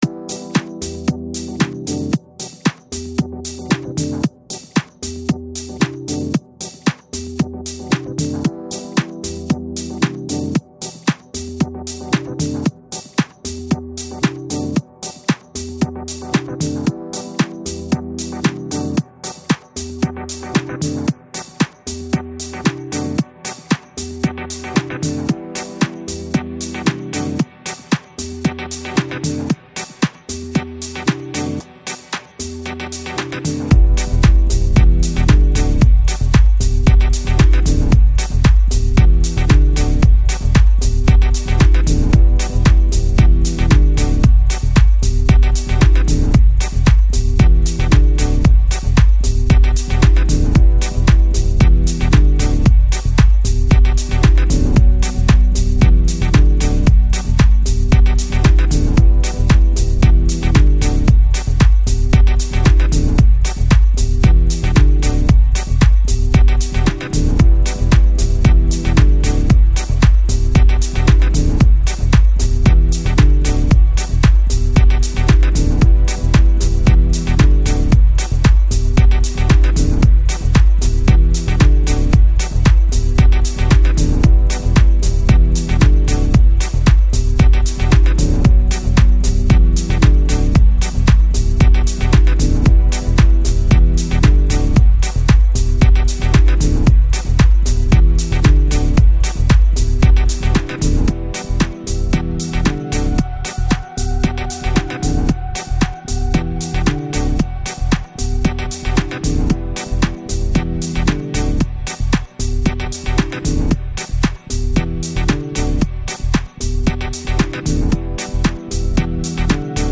Жанр: Electronic, Chill Out, Lounge, Downtempo